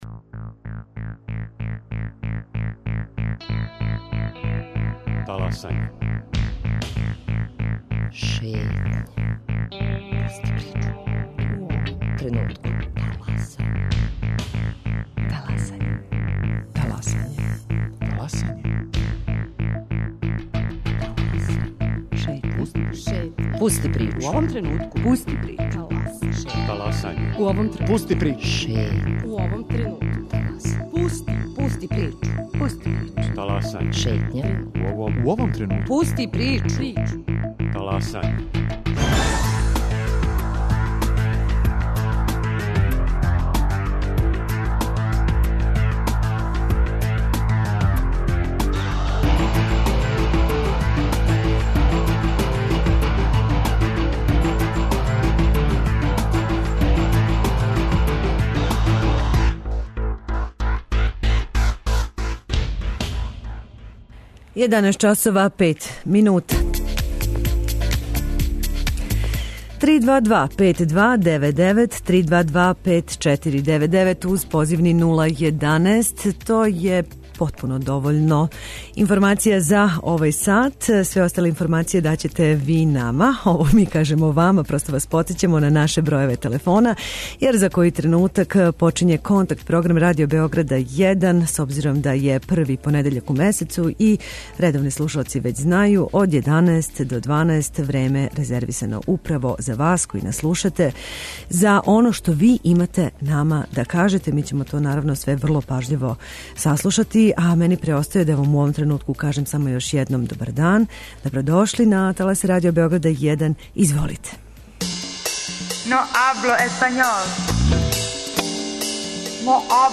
Радио Београд 1 и овог првог понедељка у месецу причу препушта својим слушаоцима!